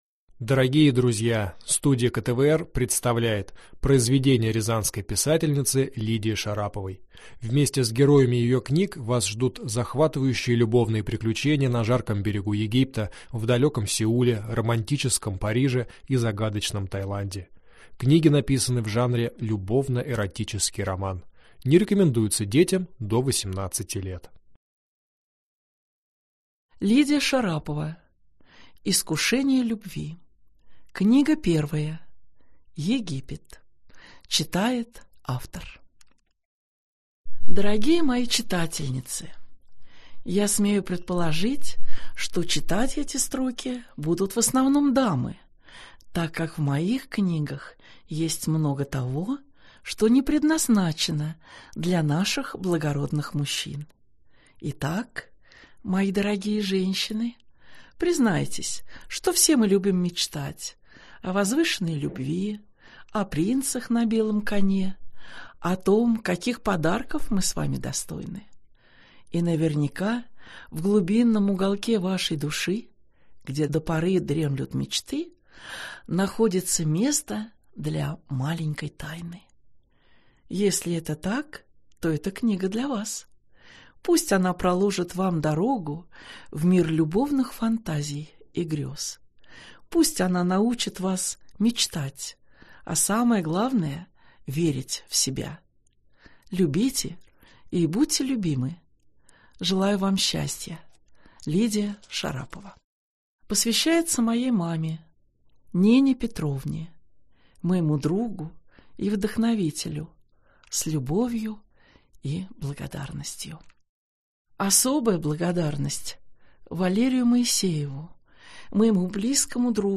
Аудиокнига Искушения Любви. Египет. Книга 1 | Библиотека аудиокниг